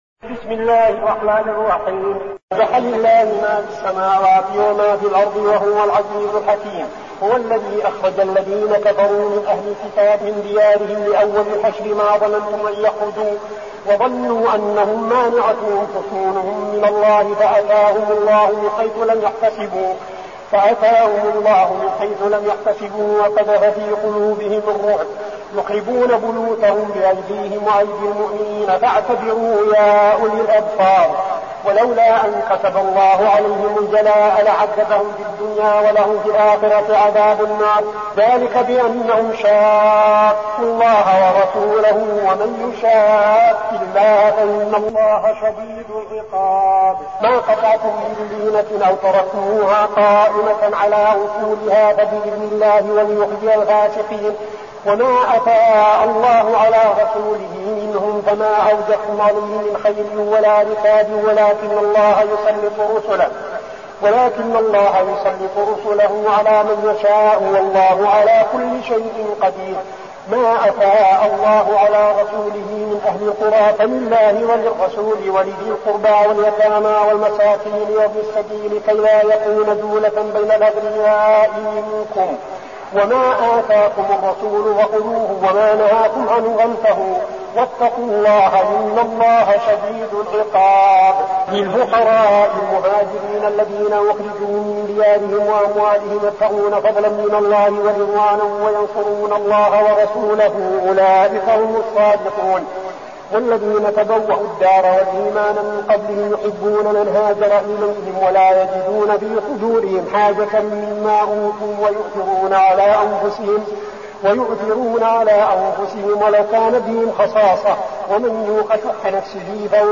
المكان: المسجد النبوي الشيخ: فضيلة الشيخ عبدالعزيز بن صالح فضيلة الشيخ عبدالعزيز بن صالح الحشر